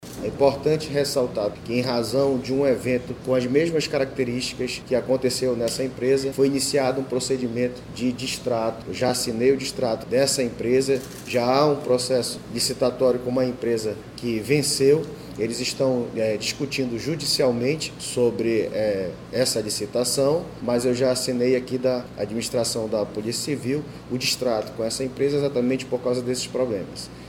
O delegado-geral adjunto da Polícia Civil, Guilherme Torres, destacou que esta não é a primeira ocorrência envolvendo problemas no parqueamento, administrado por uma empresa terceirizada. Ele informou que o contrato com a prestadora já foi encerrado.